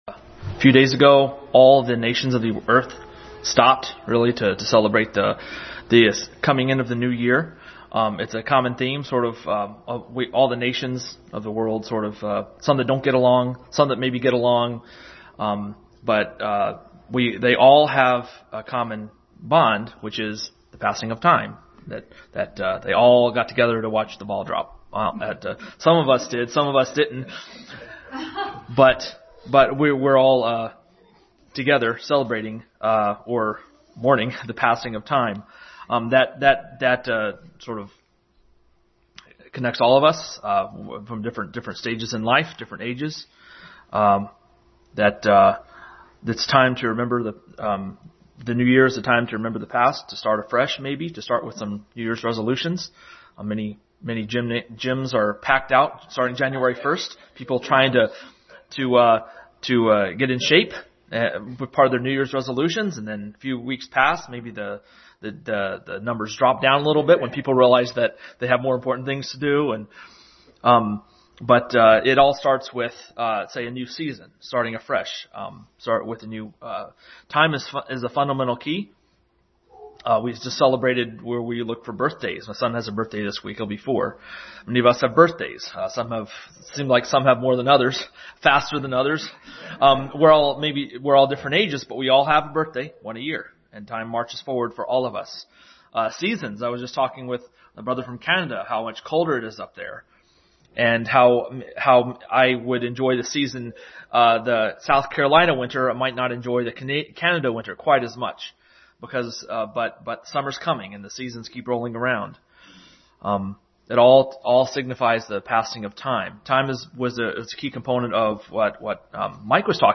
Time Passage: Ephesians 5:15, Psalm 90:3, John 1:1-3, Genesis 1:1-3, 14, Galatians 4:4-5, John 11:1-16, Proverbs 27:12, 4:23-26, 6:6-8, Isaiah 46:13 Service Type: Family Bible Hour